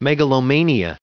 1686_megalomania.ogg